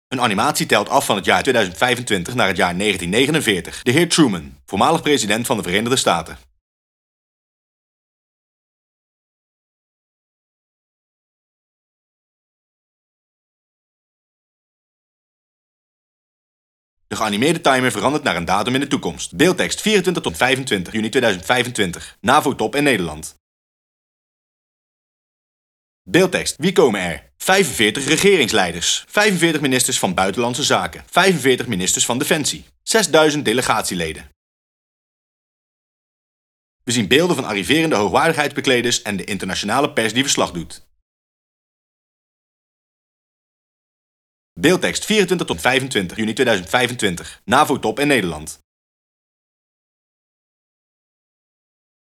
*Hoopvolle muziek speelt*
Dhr. Truman – Voormalig President van de Verenigde Staten:
De geanimeerde timer verandert naar een datum in de toekomst en de muziek verandert.
*Muziek eindigt*